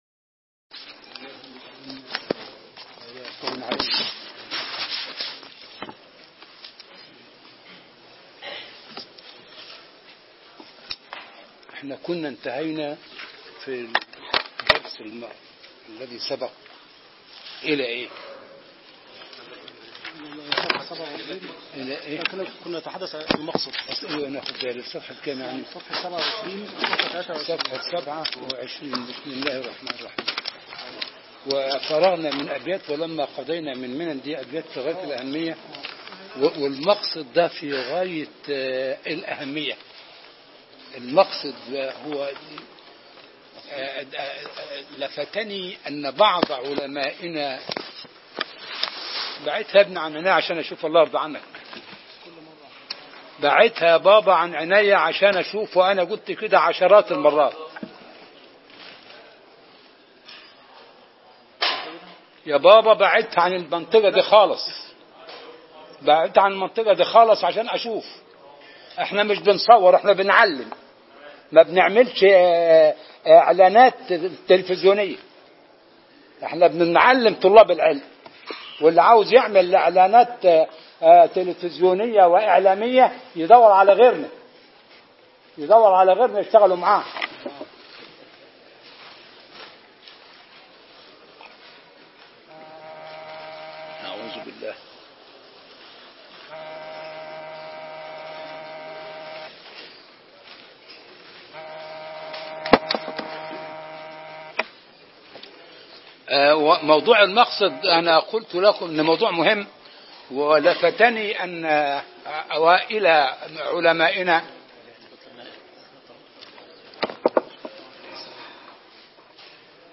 عنوان المادة الدرس الثامن (شرح كتاب أسرار البلاغة) تاريخ التحميل الثلاثاء 20 سبتمبر 2016 مـ حجم المادة 15.43 ميجا بايت عدد الزيارات 642 زيارة عدد مرات الحفظ 242 مرة إستماع المادة حفظ المادة اضف تعليقك أرسل لصديق